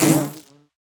sounds / mob / bee / hurt3.ogg
hurt3.ogg